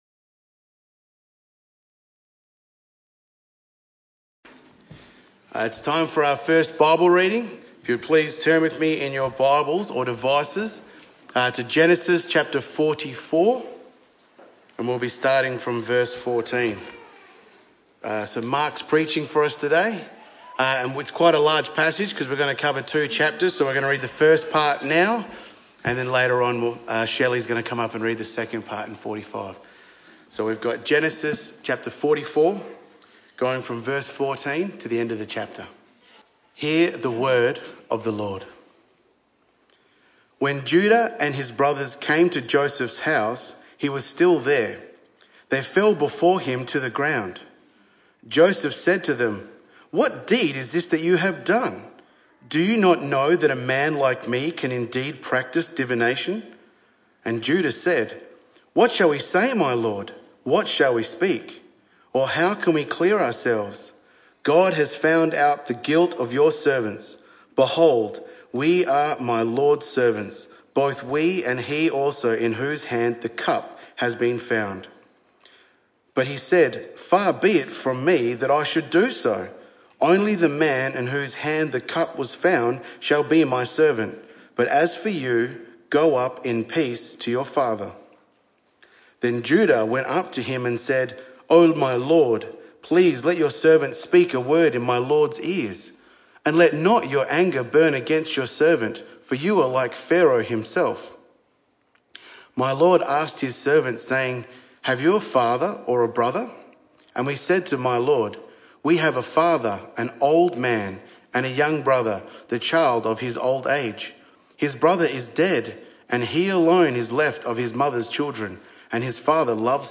This talk was part of the AM Service series entitled God’s Blessing: To Abraham And Beyond Part 3